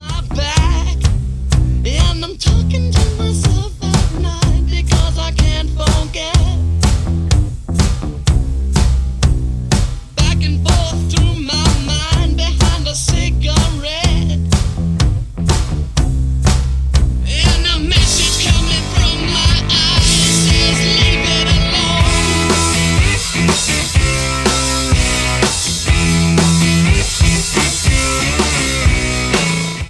• Alternative
American rock duo